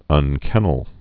(ŭn-kĕnəl)